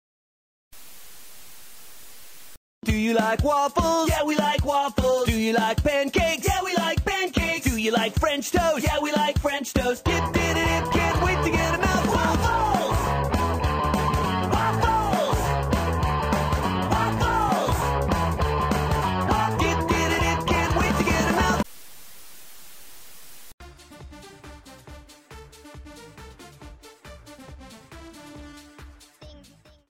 chaotic earworm